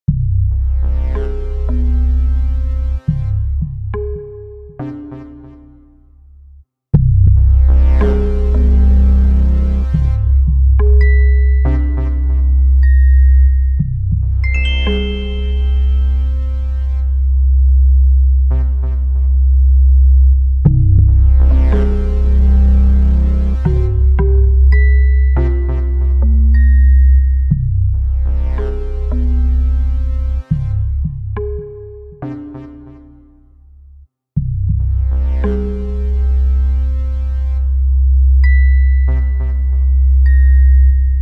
ambience.ogg